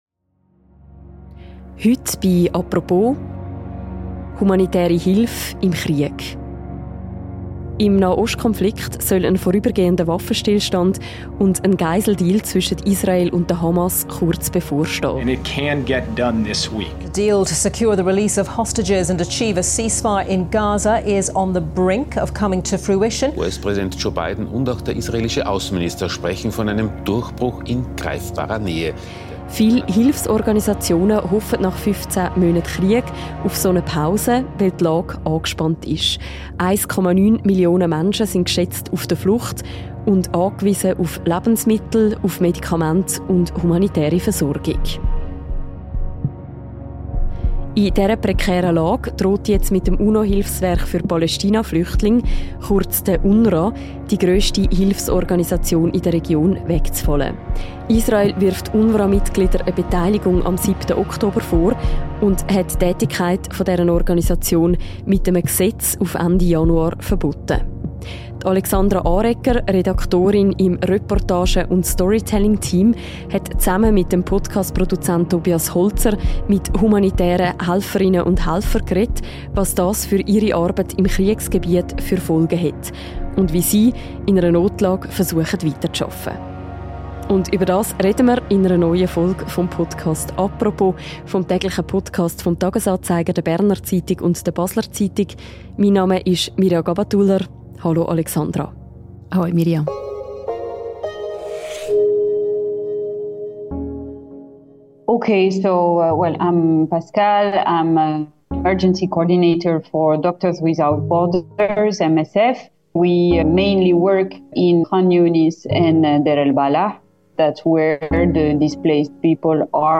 Im Podcast «Apropos» erzählen zwei humanitäre Helferinnen von Ärzte ohne Grenzen und Unicef, wie sich das auf ihre Arbeit im Kriegsgebiet auswirkt – und wie sie mit der anhaltenden Notlage umgehen.